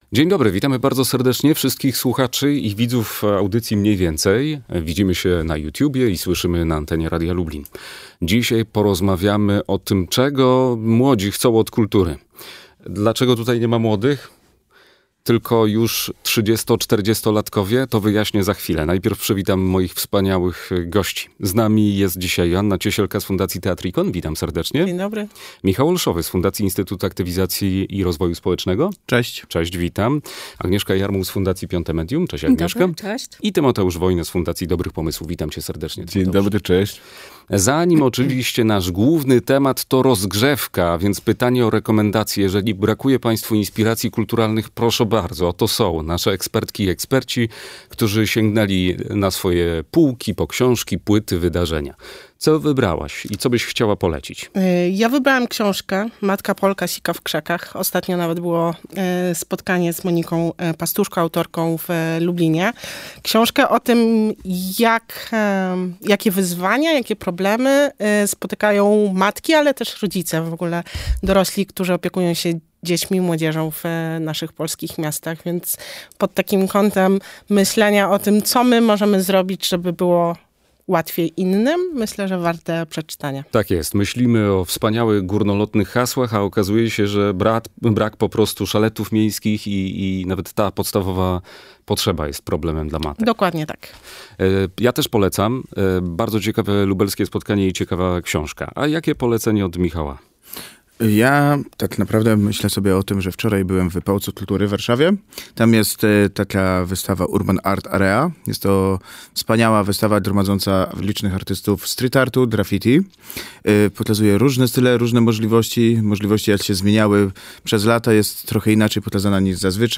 Jakie są największe wyzwania w pracy z młodzieżą? W studiu dyskutować będą: